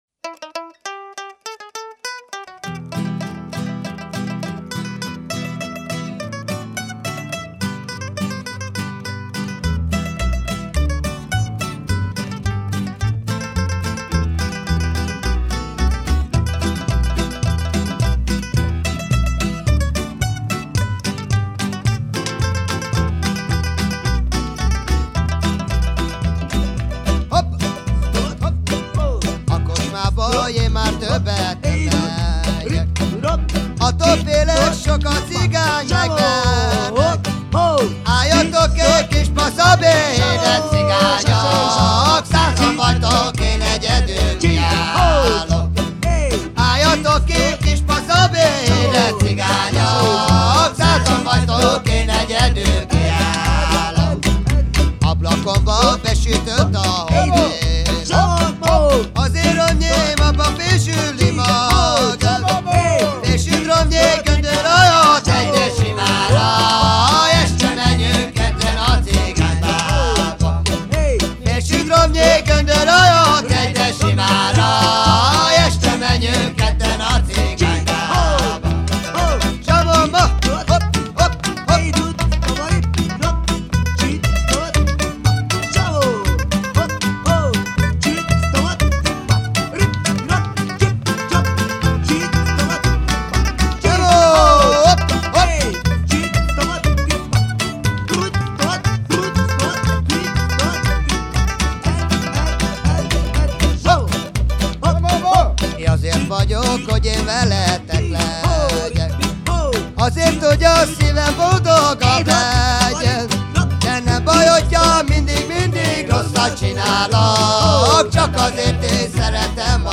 Музыка народов мира